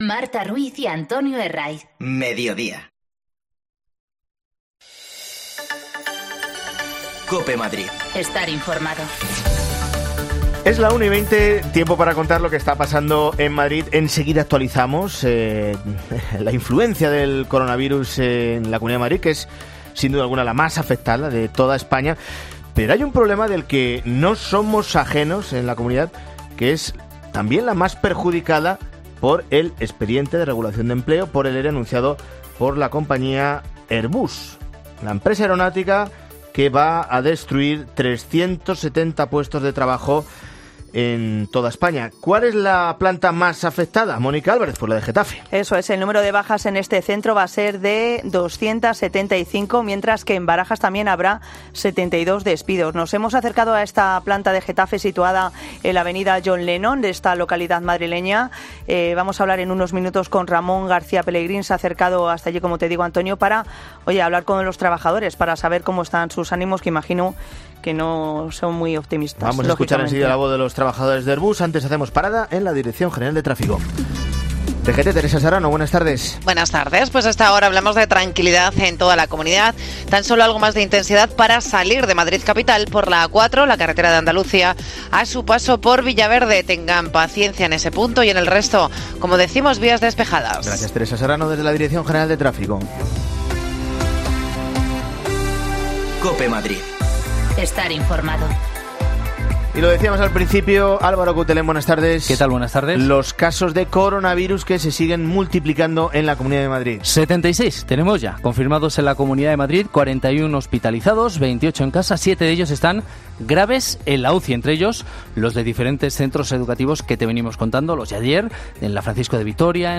AUDIO: De los 630 despidos de Airbus, 370 van a llevarse a cabo en Madrid, 275 en Getafe y 72 en Barajas. Hablamos con afectados